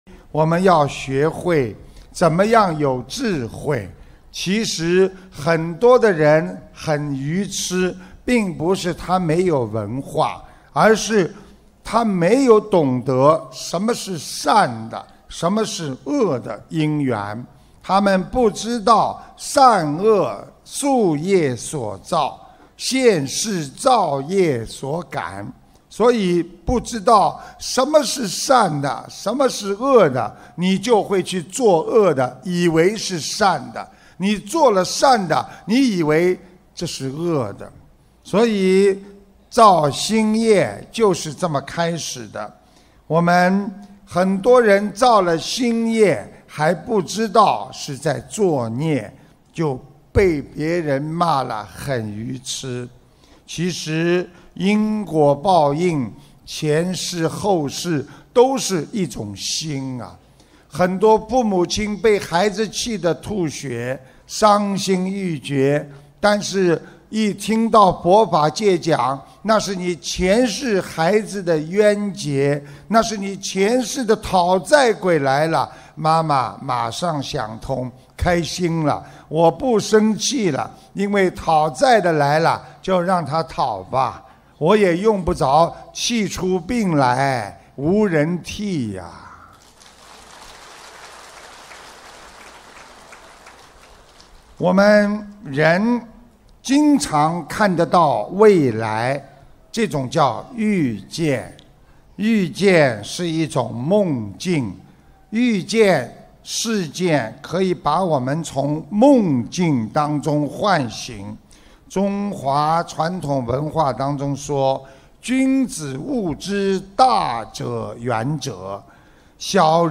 ▶ 语 音 朗 读 点击进入 ☞ 首页 > 每日 畅听 平时你们难过、烦恼， 多听听师父 的录音 ， 会笑的。